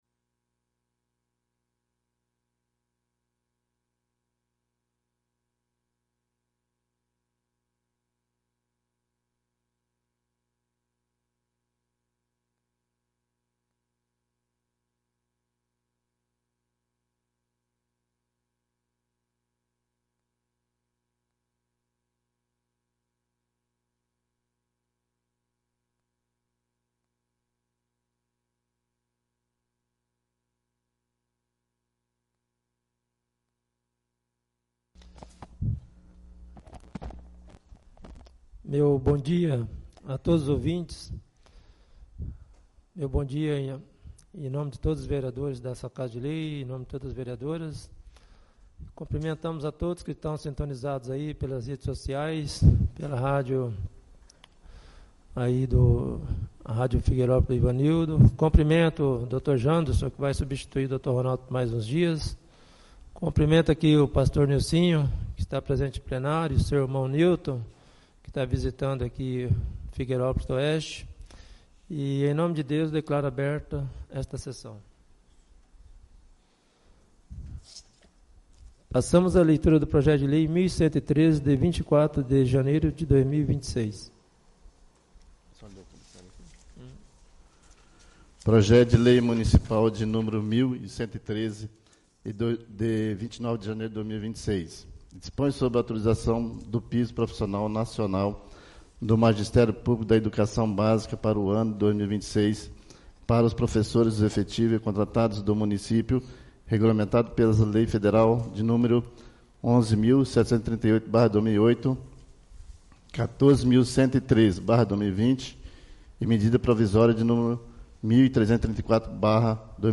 1° SESSÃO ORDINÁRIA DIA 02 DE FEVEREIRO DE 2026